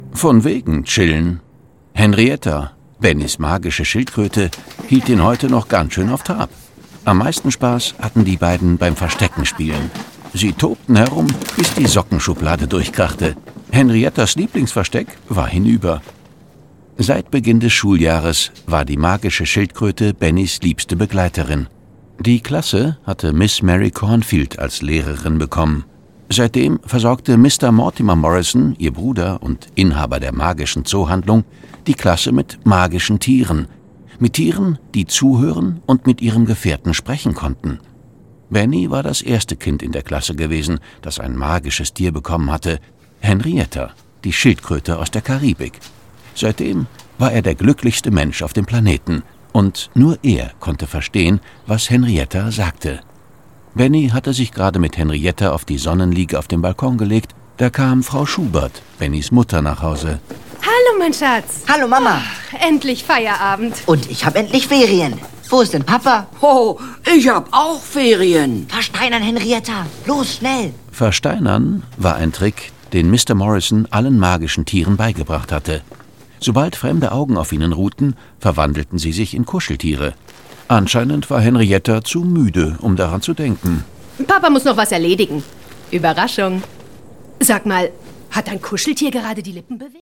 Endlich Ferien 05: Benni und Henrietta - Arne Gedigk - Hörbuch